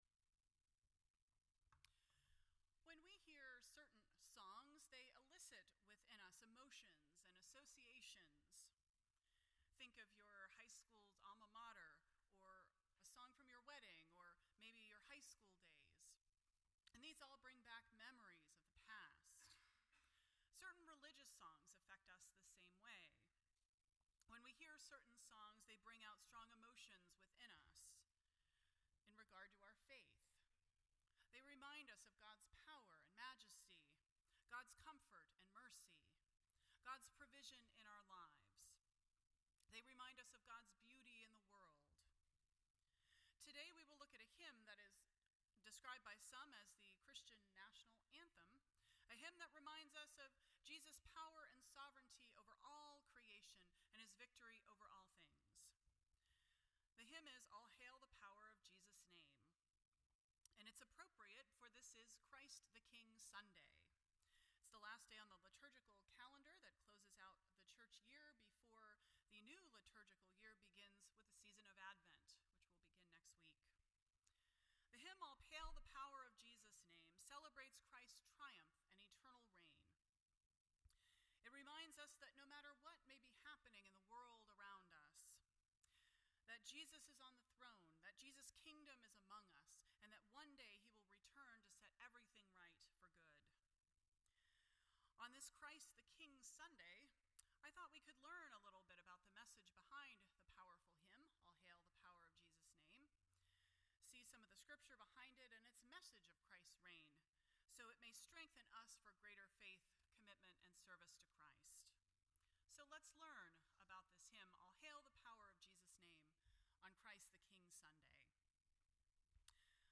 None Service Type: Sunday Morning %todo_render% Share This Story